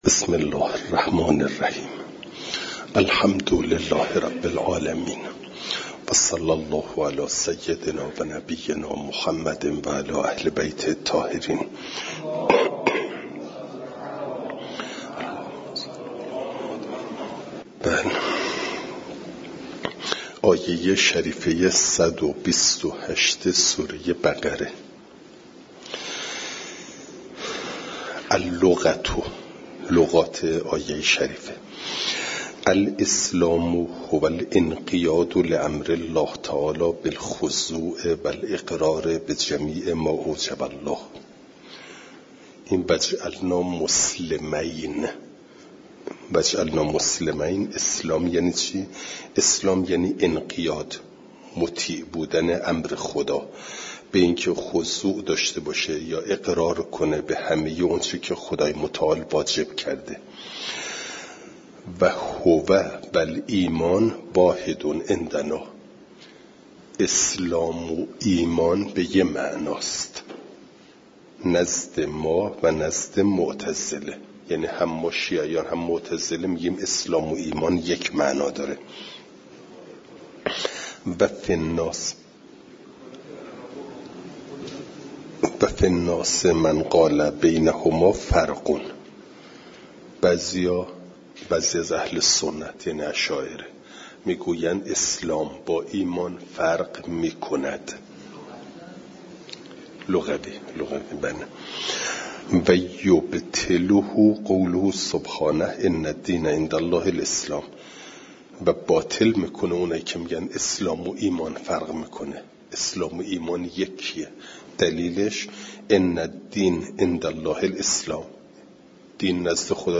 فایل صوتی جلسه صد و سی و یکم درس تفسیر مجمع البیان